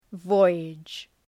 Προφορά
{‘vɔııdʒ}